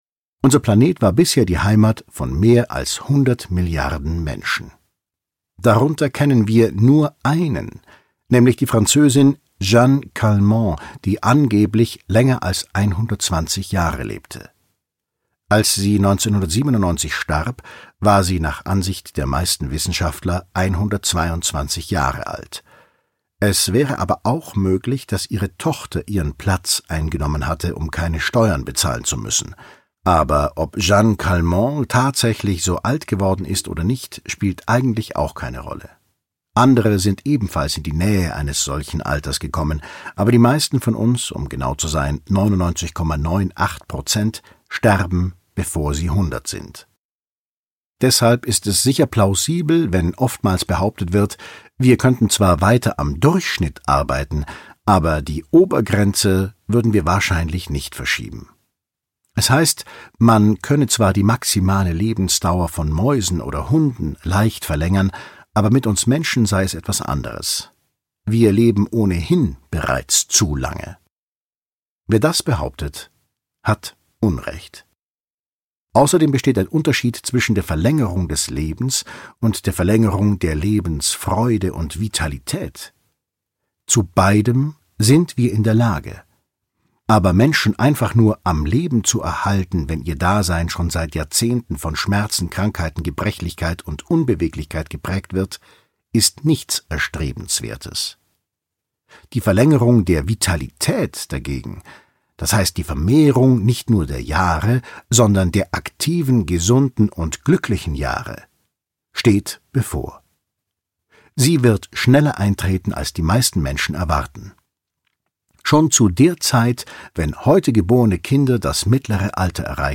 Schlagworte aging reversed • Altern • Altersforschung • Alterungsprozess • Anti Aging • Biowissenschaft • Das Ende des Alterns • David Sinclair • Die revolutionäre Medizin von morgen • DNA • Entwicklung • Evolution • Ewige Jugend • Forschung • Genetik • Gesund • Gesund bleiben • Gesundheit • gesund sein • havard professor • Heilen • Heilung • Hörbuch • Hörbuch 2020 • Hörbuch Neuerscheinung • Hundertfünfzig werden • jung • Krankheit • Krankheiten • Leben • Lebensdauer • Lebenserwartung • Lebensspanne • Lebensverlängernde Maßnahmen • Lifespan • Matthew LaPlante • Medizin • Medizin der Zukunft • Mediziner • Menschen • Naturwissenschaft • Neuerscheinung • neuerscheinung 2020 • Neuheit • Organismus • Sirtuine • Veränderung • Verjüngen • Visionär • Wissenschaft • Wissenschaftler • Wissenschaftlich • Zukunft